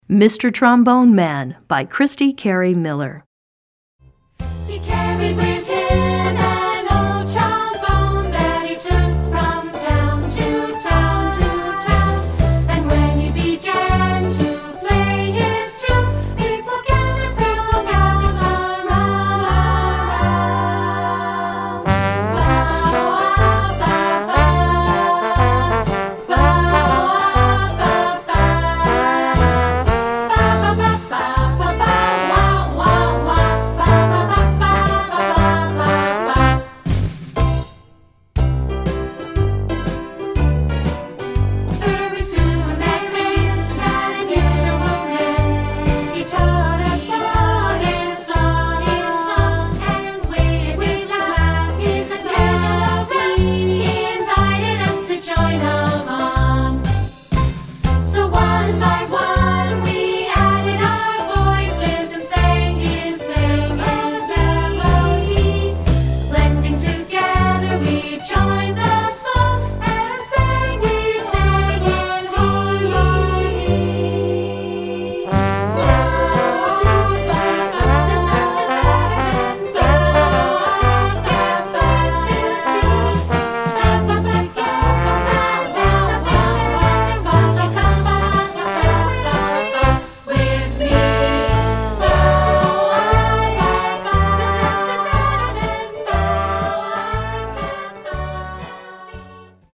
Voicing: ShowTrax CD